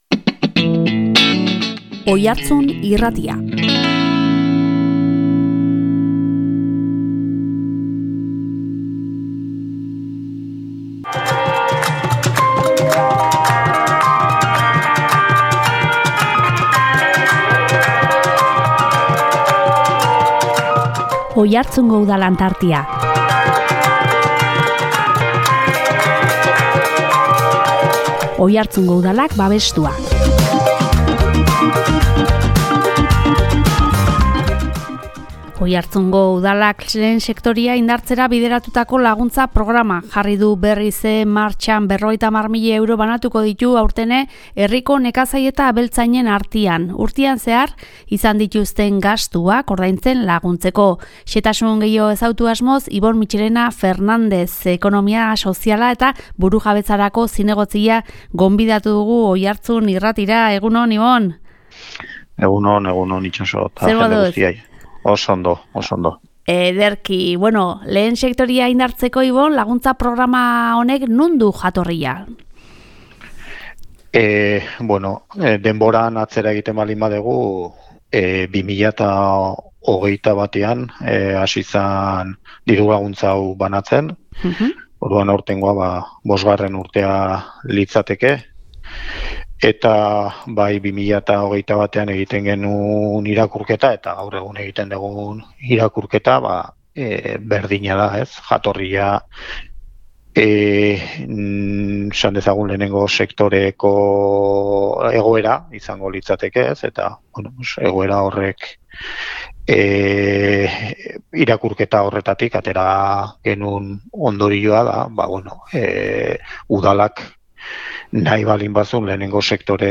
Oiartzungo Udalak lehen sektorea indartzera bideratutako laguntza programa jarri du berriz martxan. 50.000 euro banatuko ditu aurten ere herriko nekazari eta abeltzainen artean, urtean zehar izan dituzten gastuak ordaintzen laguntzeko. Xehetasun gehiago ezagutu asmoz Ibon Mitxelena Fernandez, ekonomia soziala eta burujabetzarako zinegotzia gonbidatu dugu.